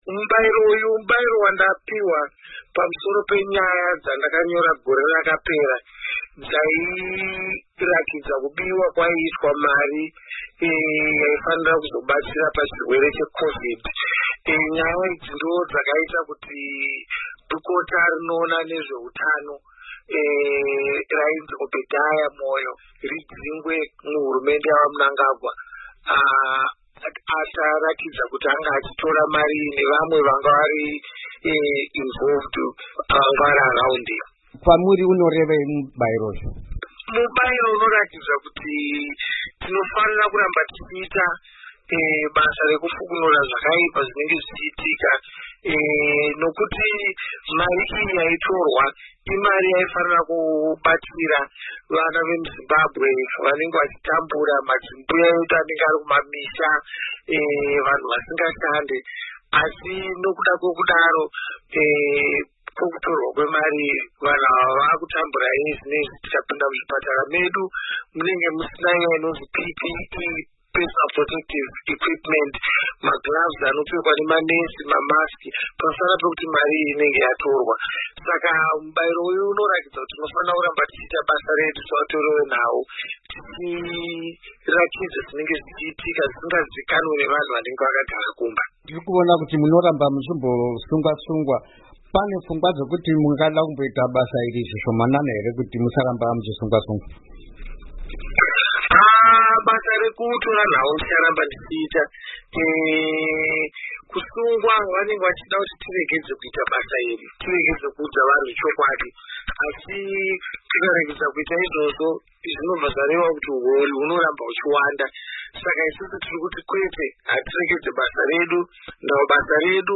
Hurukuro naHopewell Chin'ono